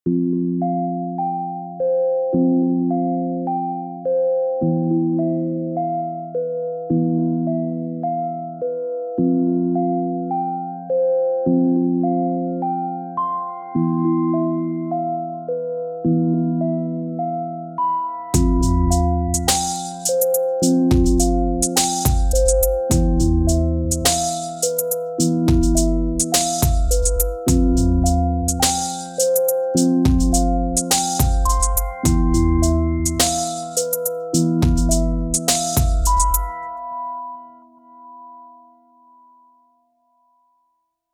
Single Layer Poly 6 mode
• Up to 6 note polyphony with pan per note